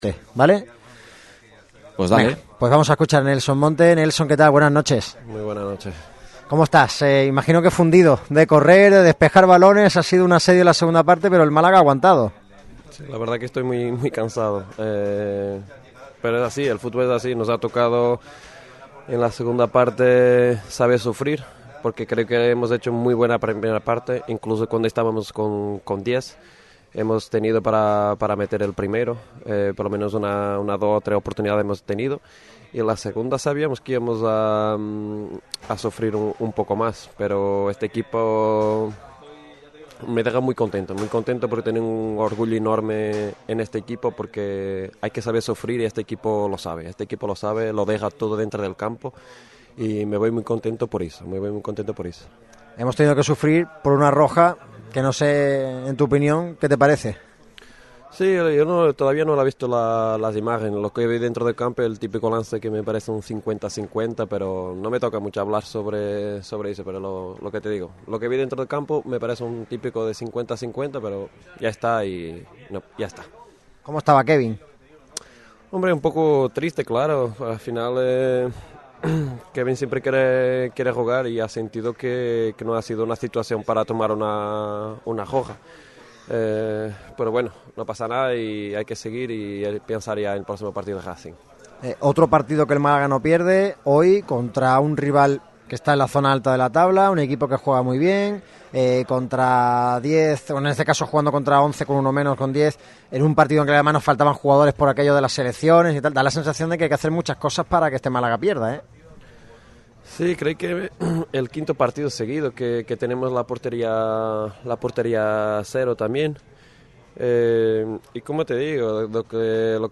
El central luso compareció al término del duelo que enfrentó en La Romareda al Málaga CF contra el Real Zaragoza.